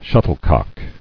[shut·tle·cock]